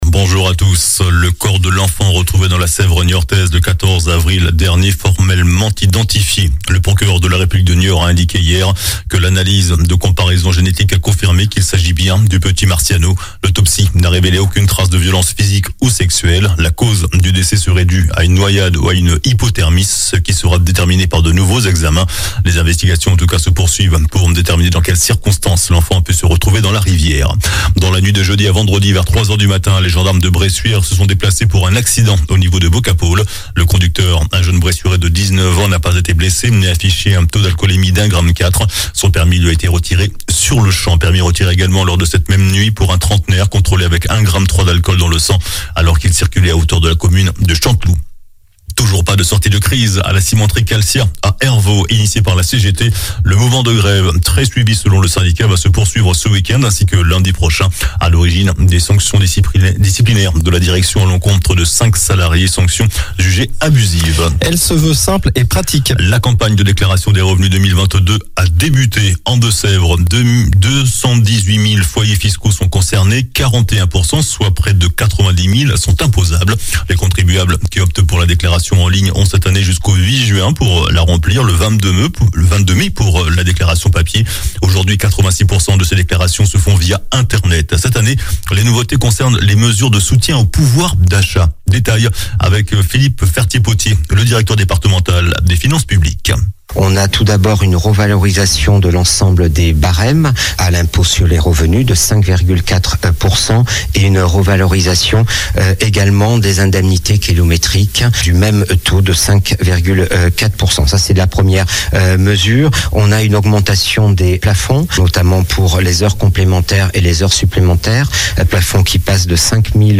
JOURNAL DU SAMEDI 22 AVRIL